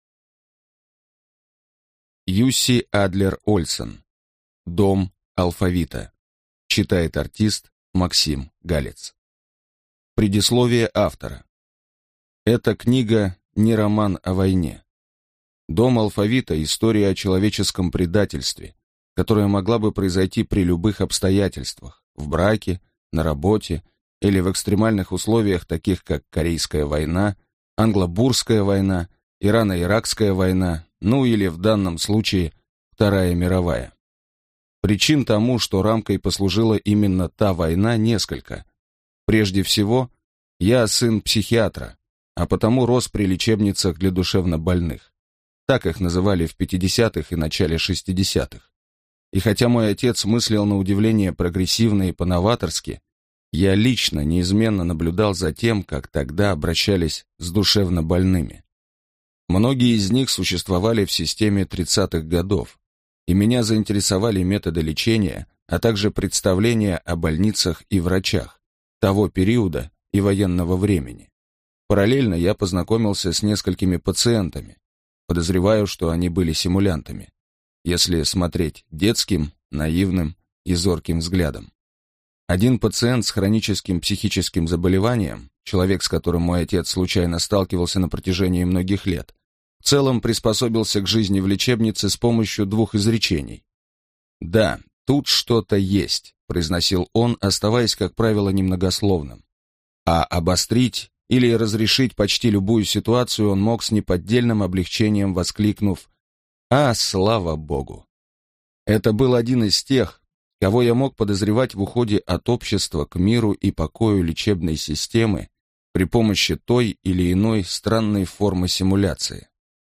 Аудиокнига Дом алфавита | Библиотека аудиокниг